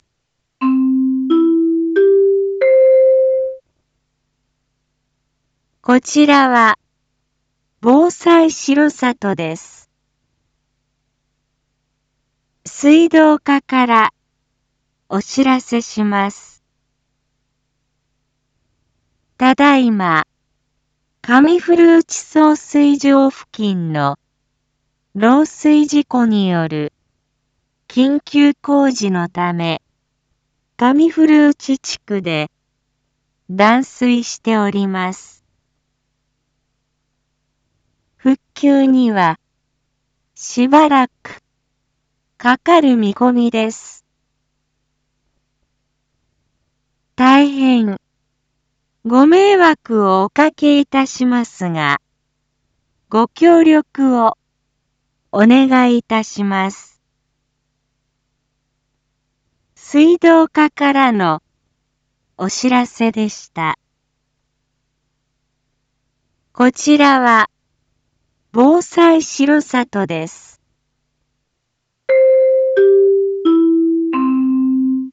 一般放送情報
Back Home 一般放送情報 音声放送 再生 一般放送情報 登録日時：2023-12-05 09:56:16 タイトル：R5.12.5 上古内地区水道断水について（地区限定配信） インフォメーション：こちらは、防災しろさとです。